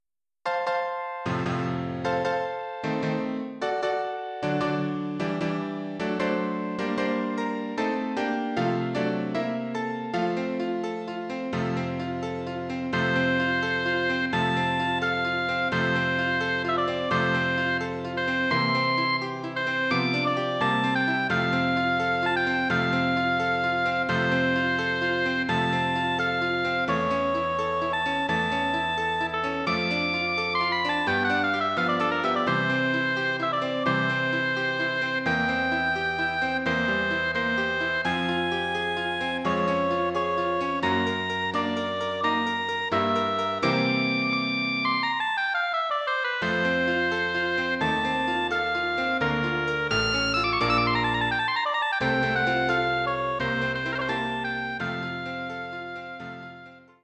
Kammermusik / Originalwerke / Soloinstrument
Besetzung: Oboe, Klavier
Instrumentation: oboe, piano